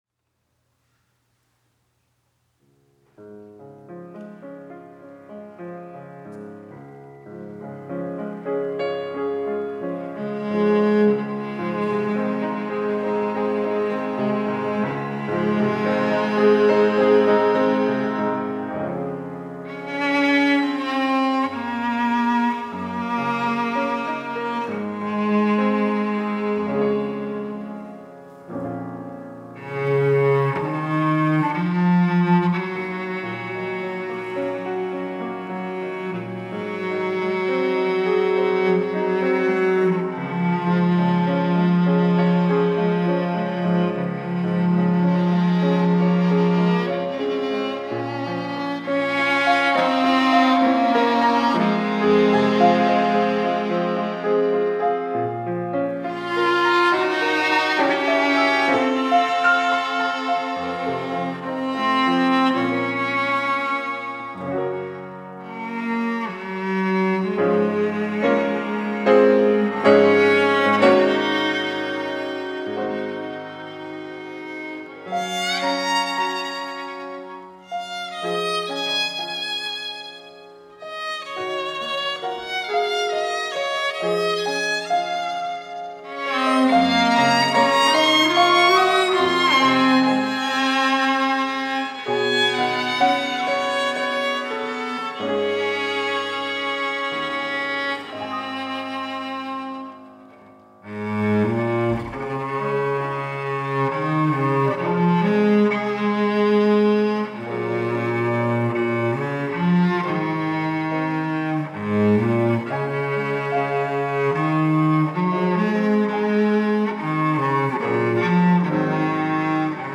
특송과 특주 - 주기도문